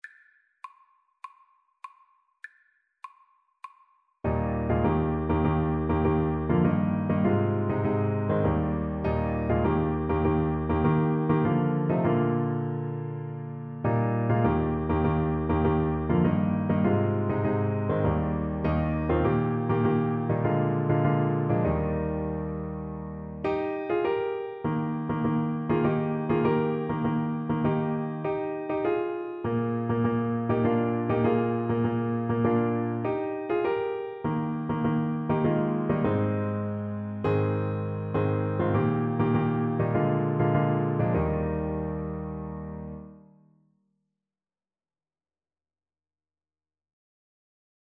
Trumpet version
4/4 (View more 4/4 Music)
Bb4-Bb5
Trumpet  (View more Easy Trumpet Music)
Classical (View more Classical Trumpet Music)